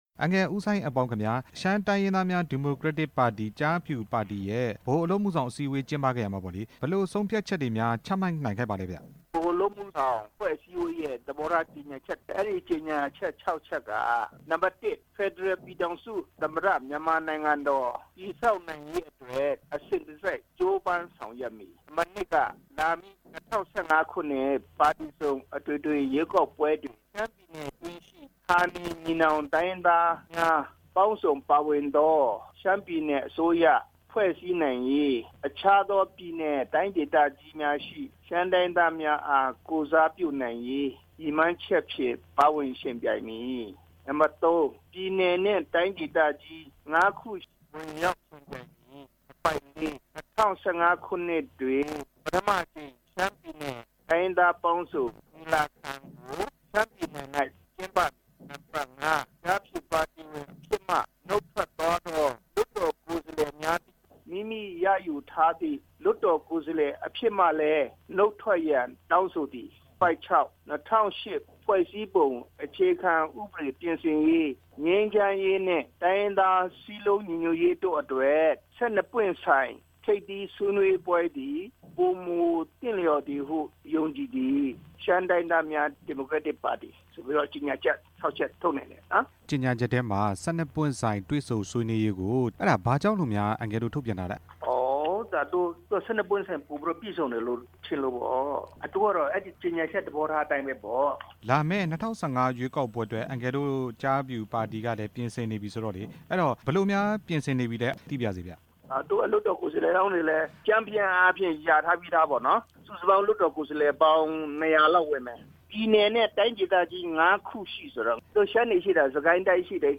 ကျားဖြူ ဗဟိုအလုပ်အမှုဆောင် အစည်းအဝေး မေးမြန်းချက်